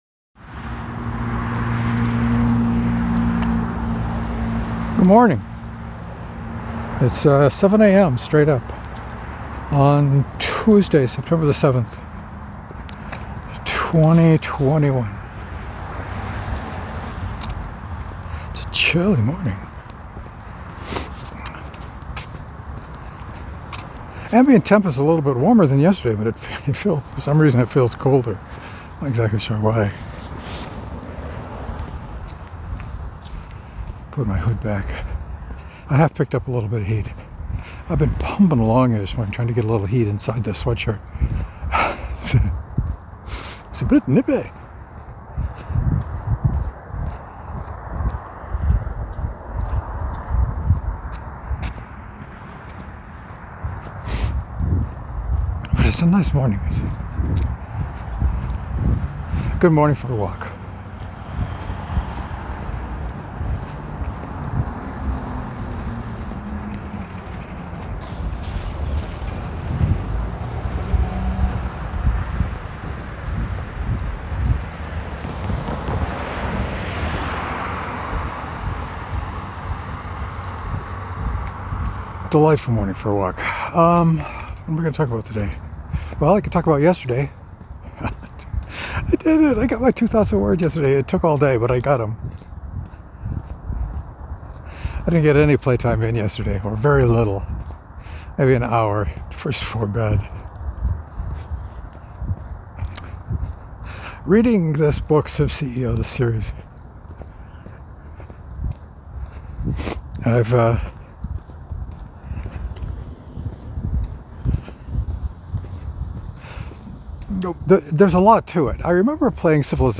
Just looking at the waveform on this morning’s audio, I think I mumbled a lot this morning.